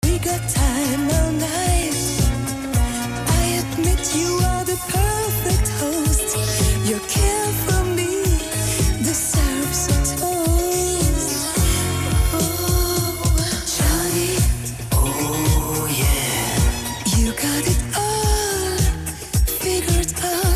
Приведем звуковые фрагменты, демонстрирующие дополнительную обработку звука.
4-virtual-dolby.mp3